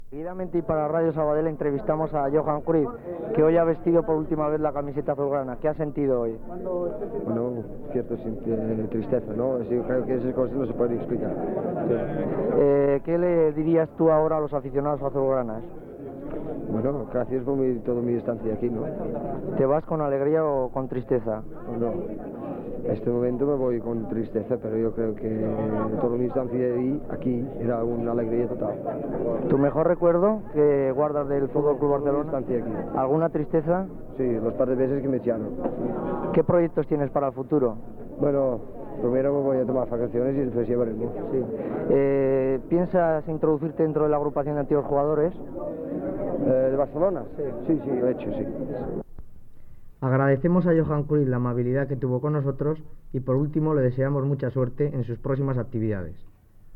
Entrevista informativa al jugador del Futbol Club Barcelona Johan Cruyff després de jugar el seu últim partit amb l'equip blau grana
Esportiu